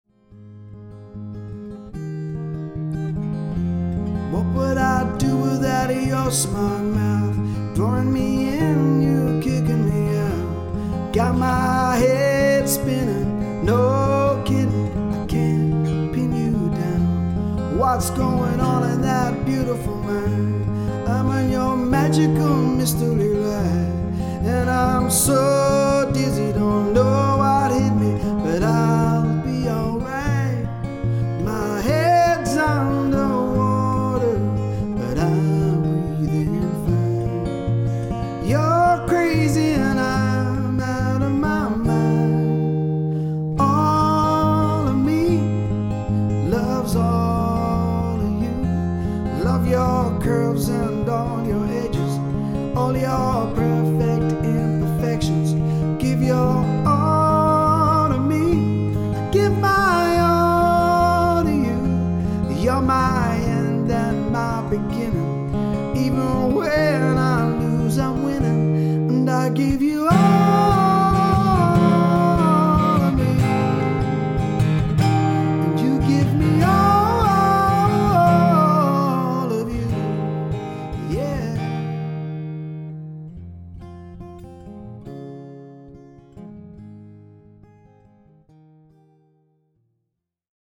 acoustic musician
rootsy grooves, blues and pop
Acoustic Cover